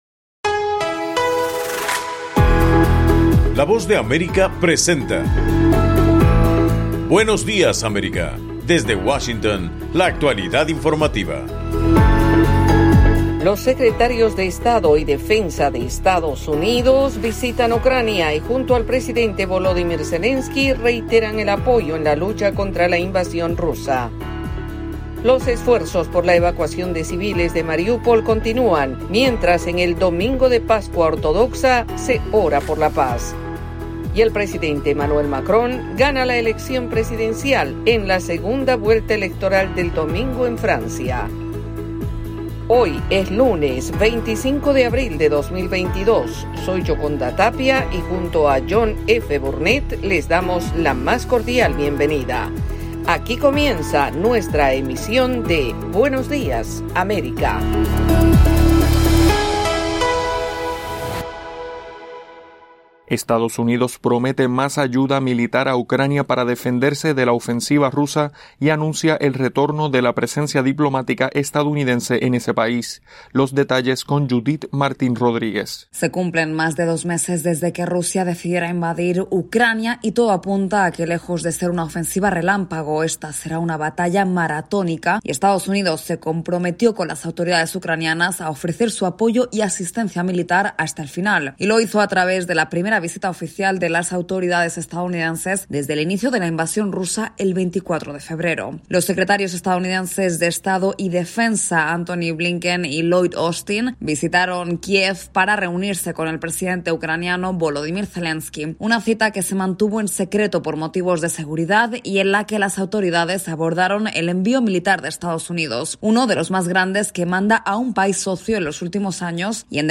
En el programa de hoy, 25 de abril, secretarios de Estado y Defensa de EE.UU. visitan Ucrania y junto al presidente Volodimyr Zelenskyy reiteran solidaridad contra la invasión rusa. Esta y otras noticias de Estados Unidos y América Latina en Buenos Días América, un programa de la Voz de América.